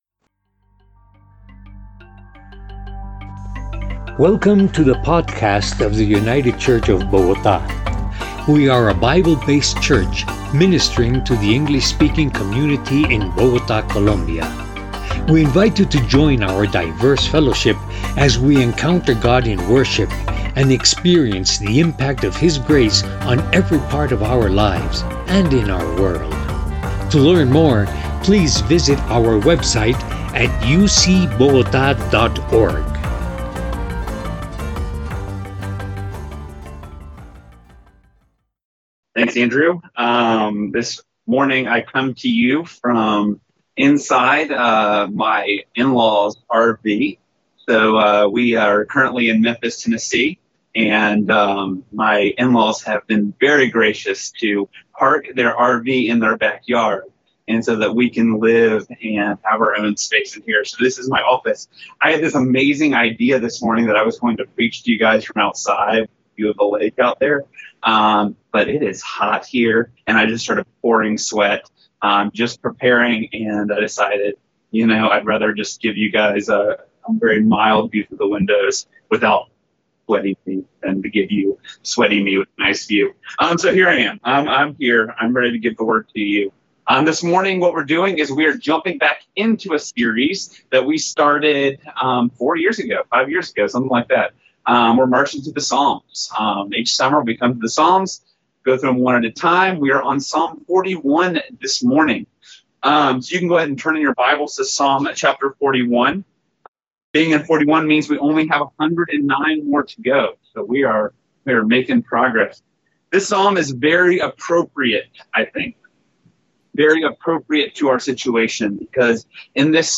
By United Church of Bogotá | 2020-07-19T16:29:13-05:00 June 14th, 2020 | Categories: Sermons | Tags: Summer Psalms | Comments Off on Mercy for the Undeserving Share This Story, Choose Your Platform!